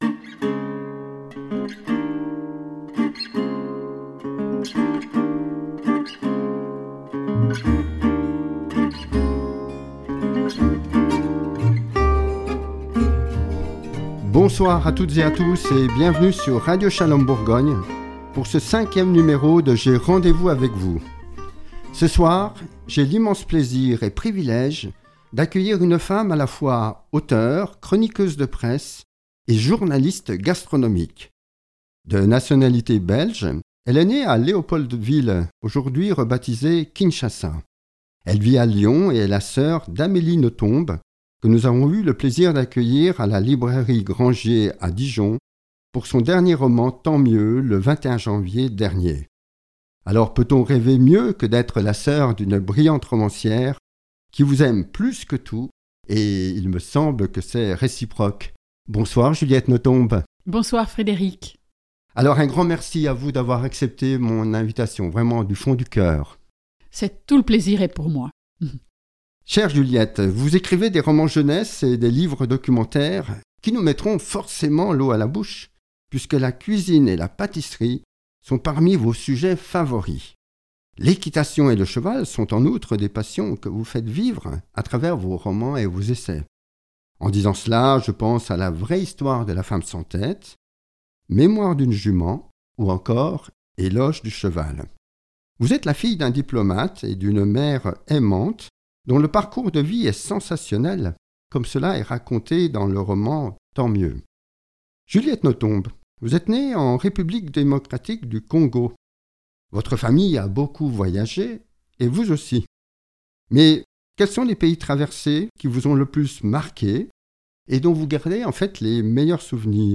Une heure trente d'interview passionante autour de deux de ses romans : '' La Vraie hsitoire de la Femme sans tête '' et '' Mémoires d'une jument ''.